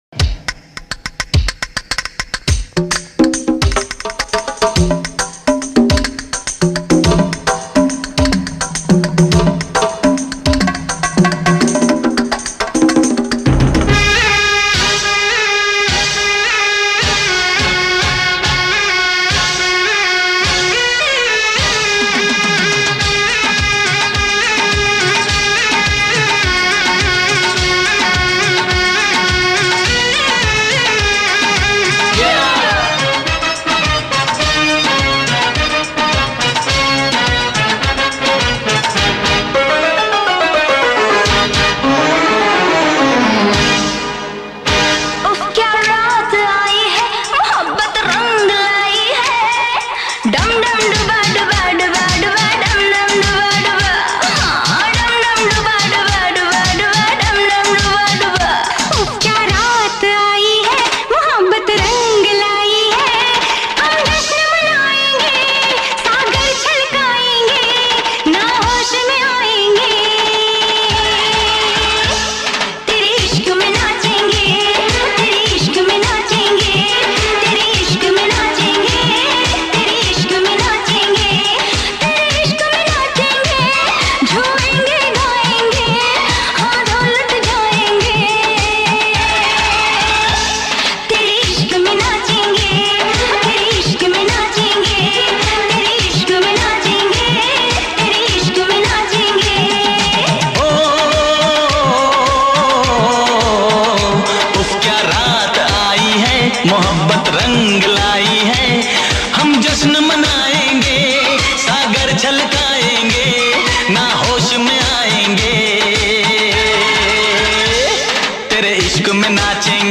آهنگ هندی قدیمی شاد